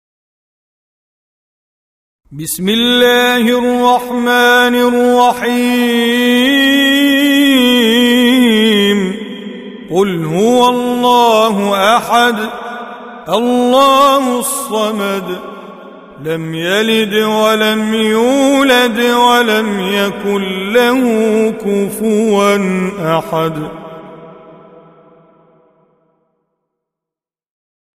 Surah Repeating تكرار السورة Download Surah حمّل السورة Reciting Mujawwadah Audio for 112. Surah Al-Ikhl�s or At-Tauh�d سورة الإخلاص N.B *Surah Includes Al-Basmalah Reciters Sequents تتابع التلاوات Reciters Repeats تكرار التلاوات